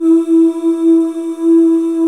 Index of /90_sSampleCDs/Club-50 - Foundations Roland/VOX_xMaleOoz&Ahz/VOX_xMale Ooz 1S